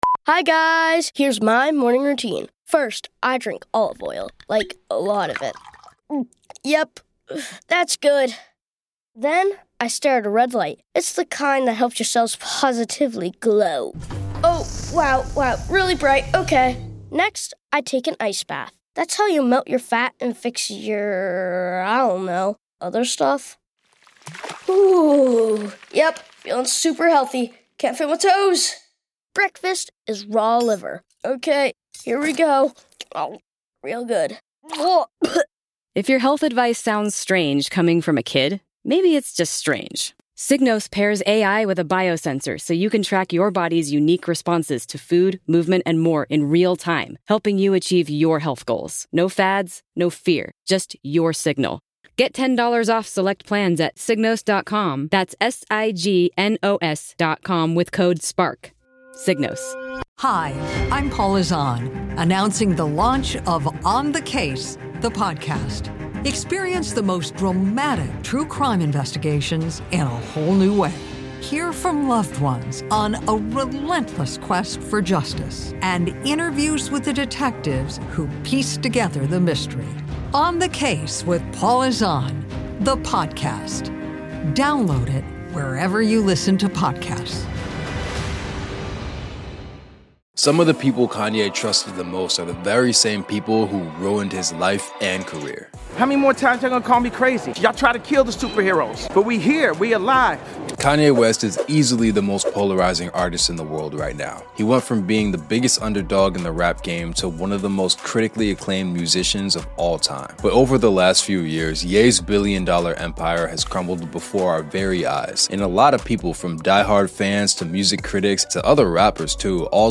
🎤 Featuring real interviews, clips, and documented moments, we expose the hidden fractures inside Kanye's world.